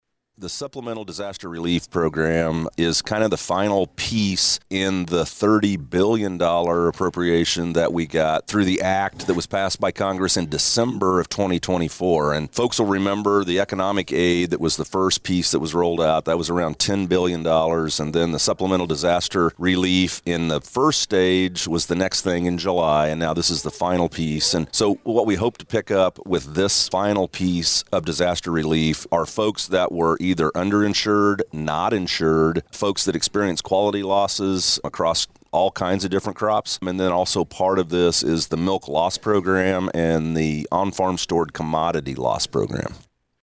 (NAFB)  The U.S. Department of Agriculture announced the Supplemental Disaster Relief Program to help producers cover losses from 2023 and 2024 disasters. Richard Fordyce, USDA Undersecretary for Farm Production and Conservation, talked about the SDRP.